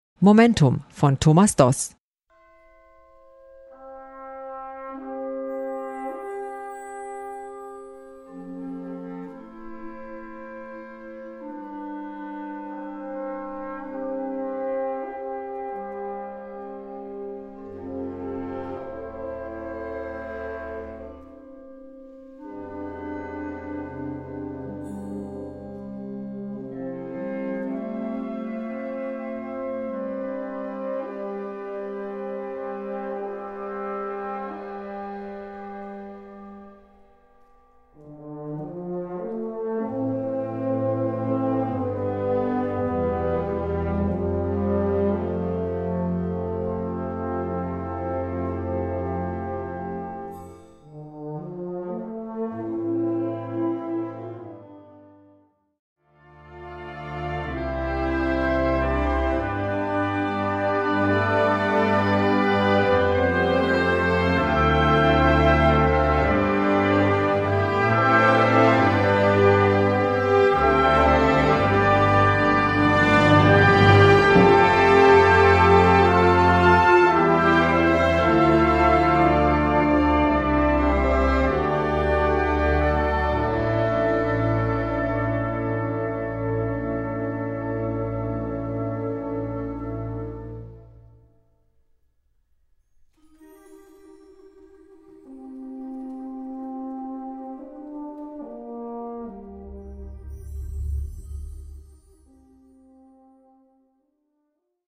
Gattung: Konzertwerk
Besetzung: Blasorchester
Ein idealer Ruhepunkt in Ihrem Konzertprogramm!